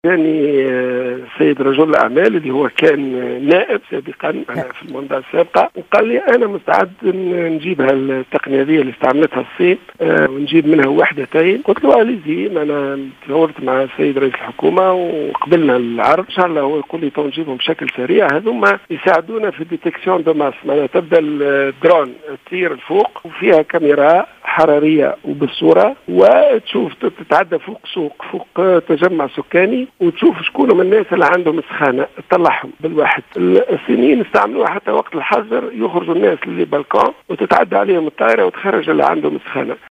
أكد وزير الصحة عبد اللطيف المكي في مداخلة له صباح اليوم على الإذاعة الوطنية أن أحد رجال الأعمال قرر التبرع بطائرات مسيرة "درون" لتتبع حالات الإصابة بفيروس كورونا و الكشف عن المصابين وهي تقنية استعملتها الصين.